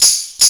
TAMB LOOP2-L.wav